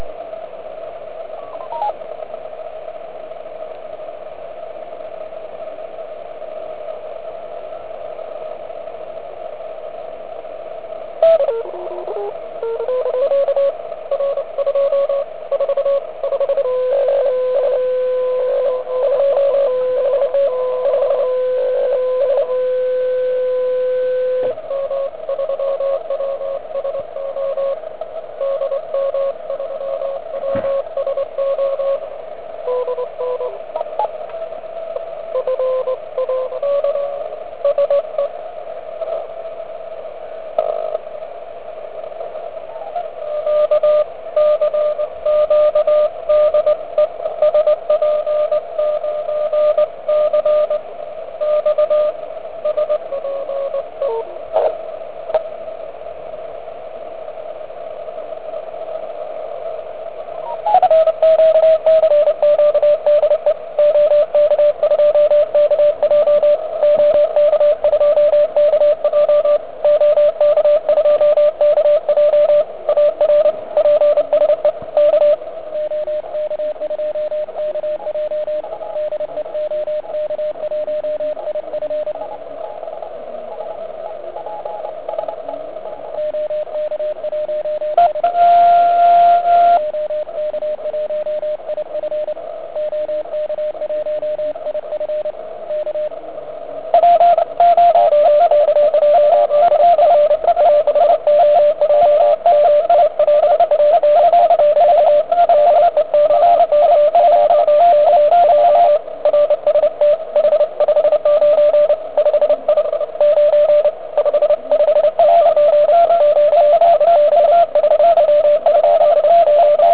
Historicky první spojeni na ATS-3B